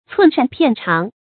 寸善片长 cùn shàn piàn cháng
寸善片长发音
成语注音 ㄘㄨㄣˋ ㄕㄢˋ ㄆㄧㄢˋ ㄔㄤˊ